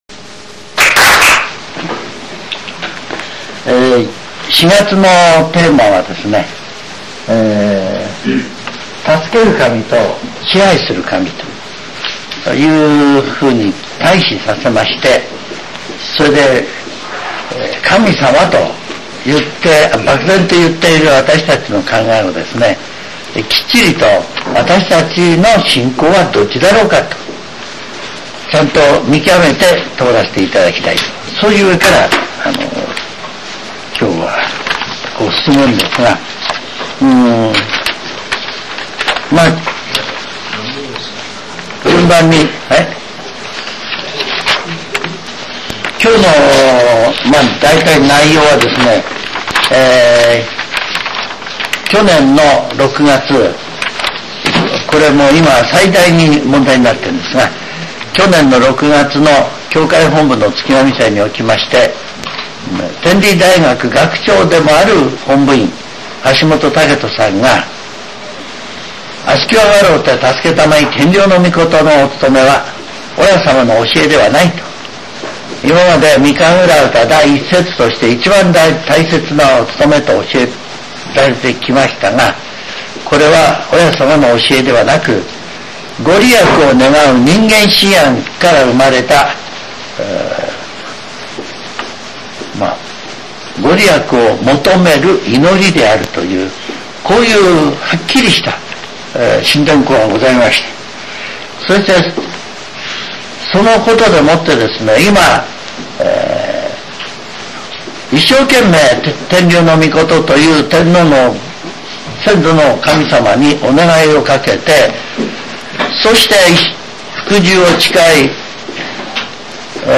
全70曲中51曲目 ジャンル: Speech